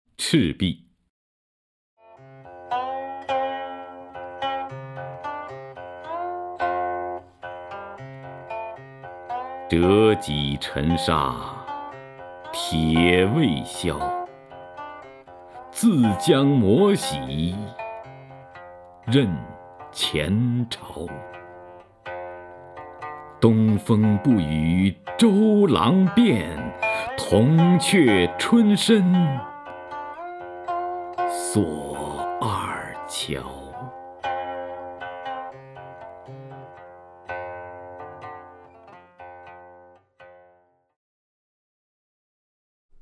徐涛朗诵：《赤壁》(（唐）杜牧) （唐）杜牧 名家朗诵欣赏徐涛 语文PLUS